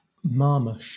Ääntäminen
Southern England
IPA : /ˈmɑːməʃ/